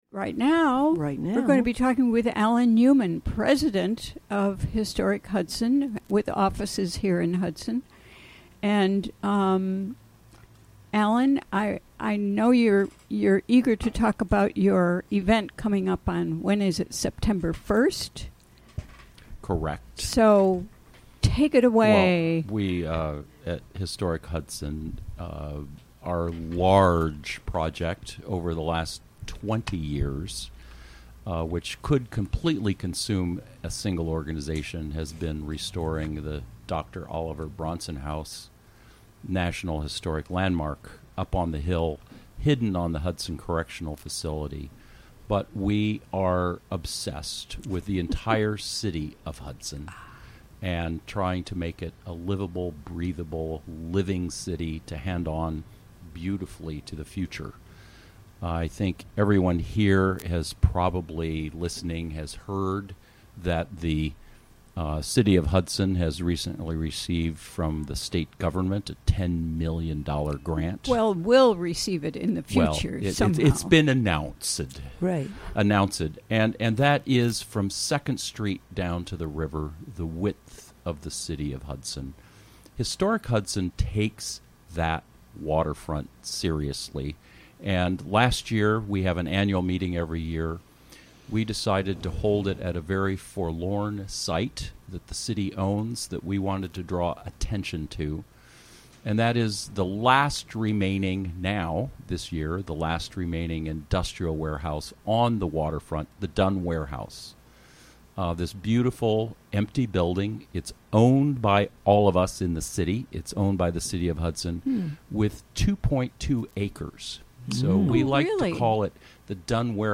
6pm The show features local news, interviews with comm...